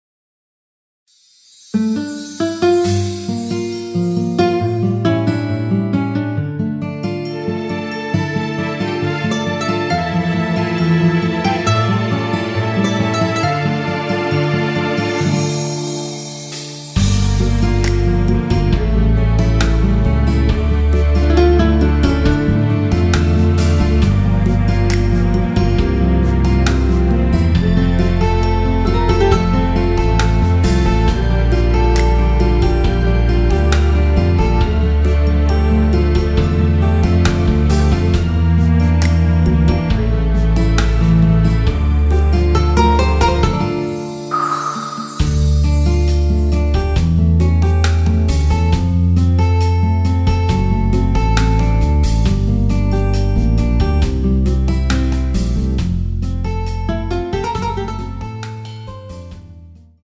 Dm
앞부분30초, 뒷부분30초씩 편집해서 올려 드리고 있습니다.
중간에 음이 끈어지고 다시 나오는 이유는